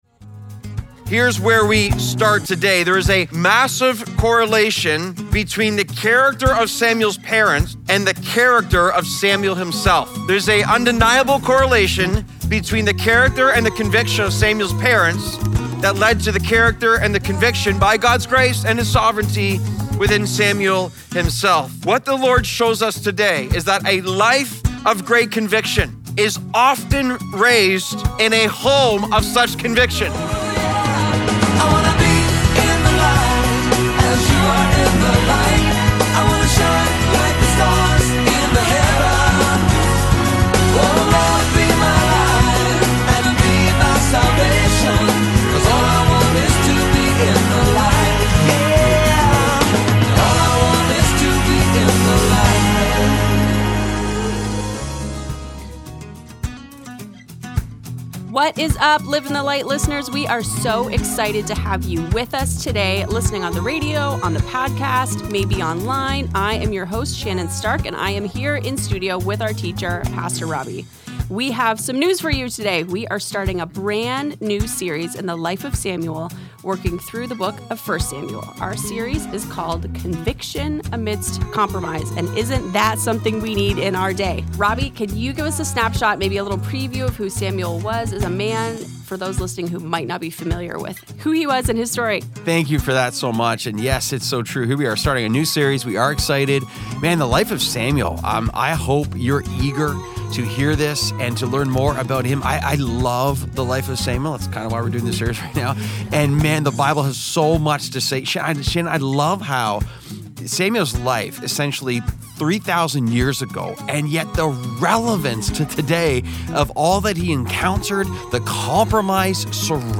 Live in the Light Daily Broadcast